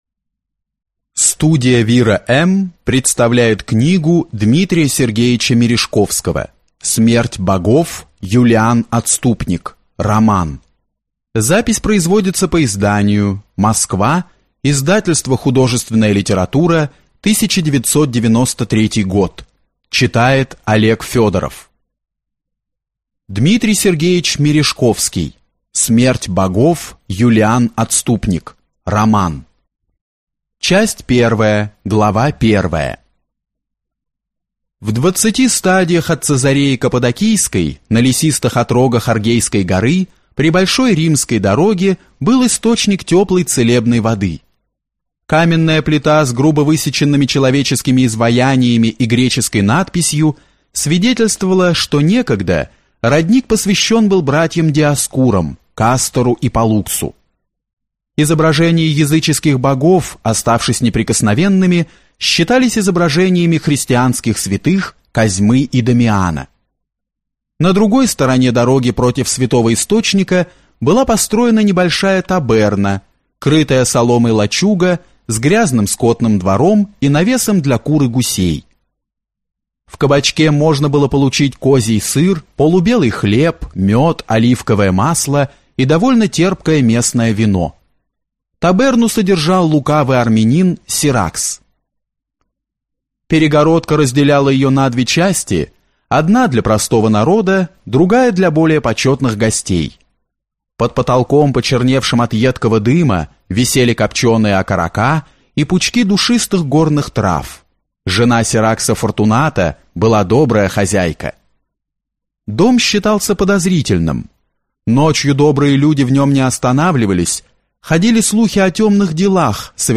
Аудиокнига Смерть Богов. Юлиан отступник | Библиотека аудиокниг